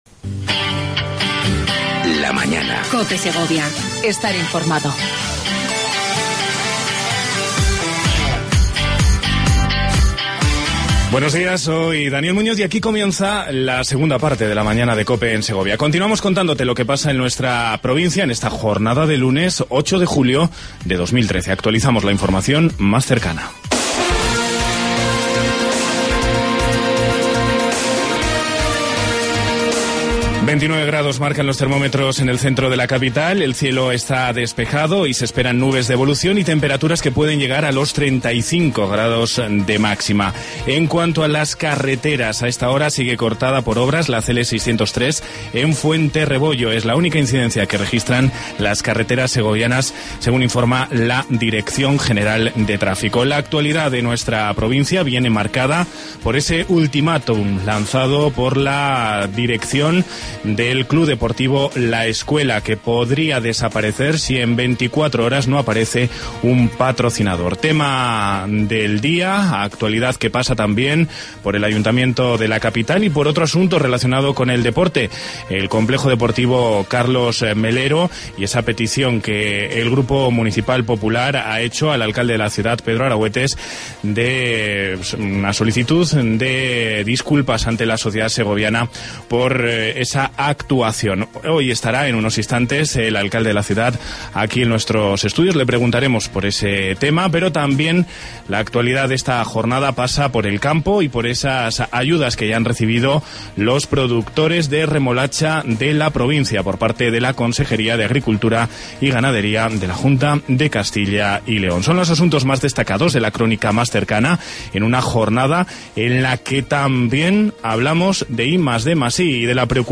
AUDIO: Entrevista con Pedro Arahuetes, Alcalde de Segovia